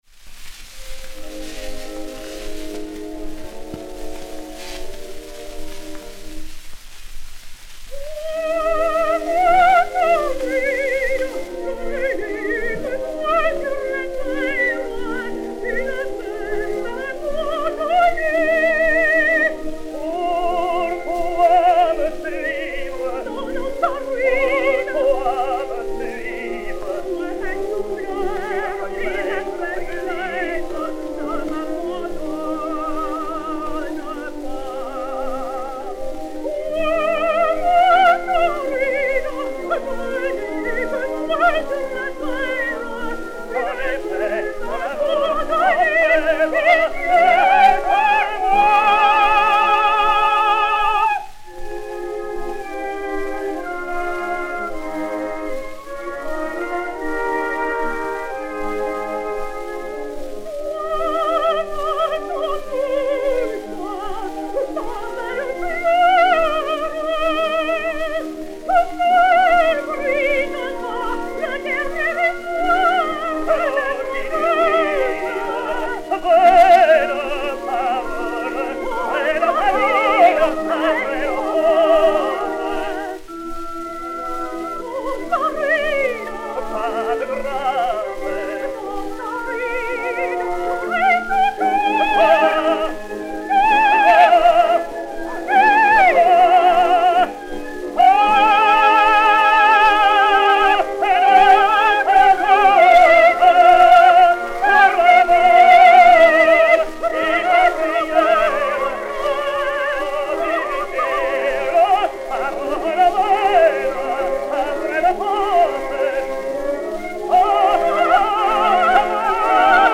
Suzanne Brohly (Santuzza) et Orchestre
Disque Pour Gramophone 033090, mat. 01327v, enr. à Paris le 25 février 1910